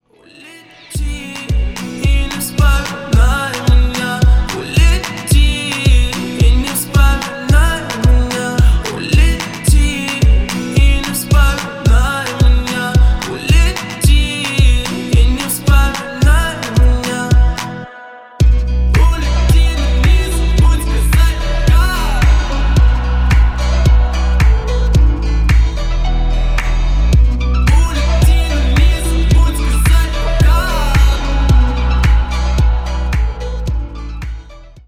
• Качество: 128, Stereo
поп
гитара
ритмичные
мужской вокал
Хип-хоп
романтичные